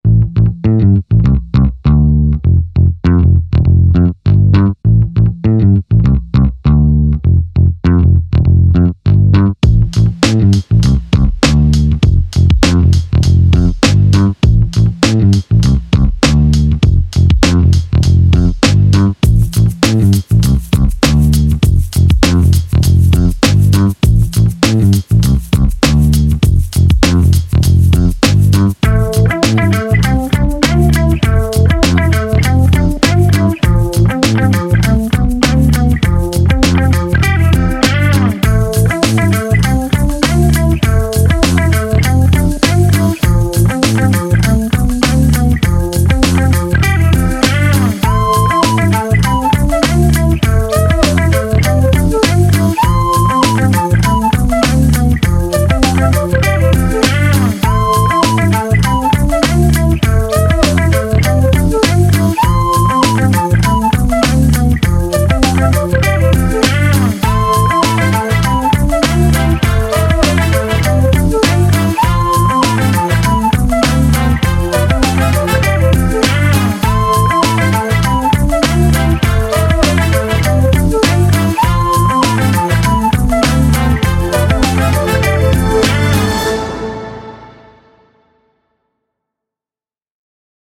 מקצבים שבניתי על קורג.